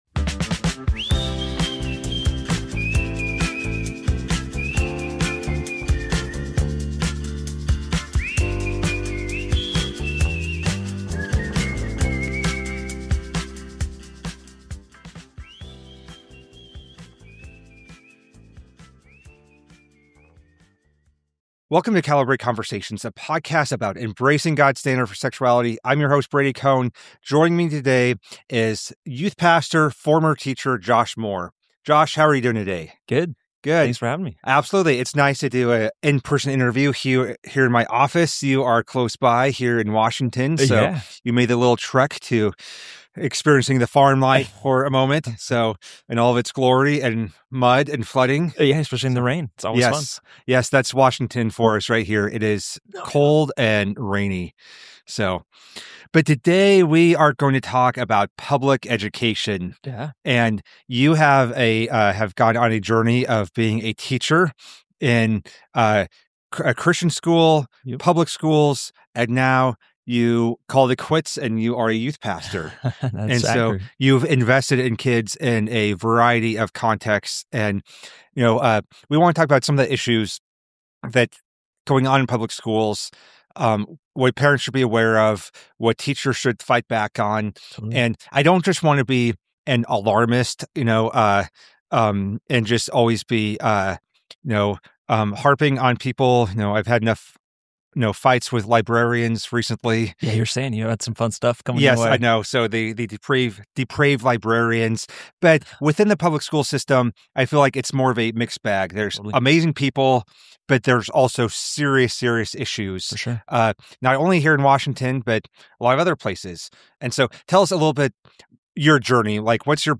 This is an honest, compassionate conversation from someone who was on the inside—and ultimately left the classroom to serve students as a youth pastor.